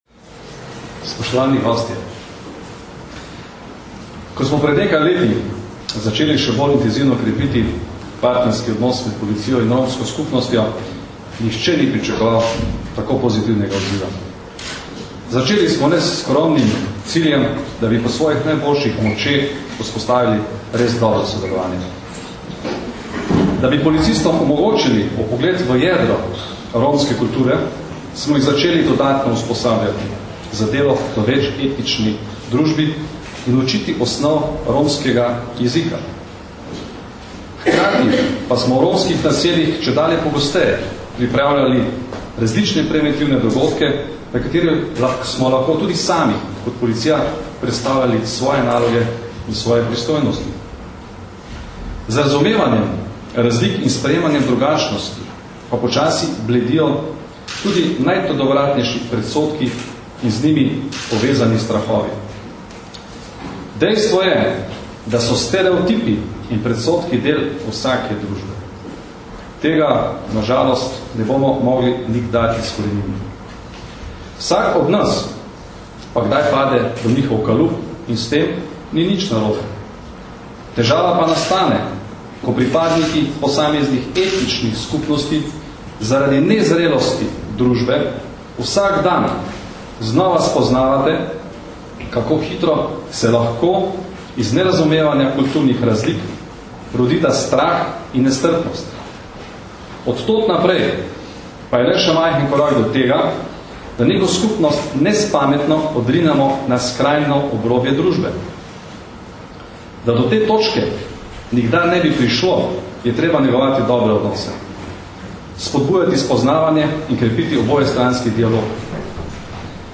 Ob bližajočem se svetovnem dnevu Romov smo v Policijski akademiji danes, 5. aprila 2012, predstavili dokumentarni film Du baro mesto avtorice Šarenke Hudorovac in odprli fotografsko razstavo Utrinki iz romskega življenja.
Zvočni posnetek nagovora (mp3)
Kot je ob današnji priložnosti dejal generalni direktor policije Janko Goršek, z razumevanjem razlik in sprejemanjem drugačnosti počasi bledijo tudi najtrdovratnejši predsodki in z njimi povezani strahovi.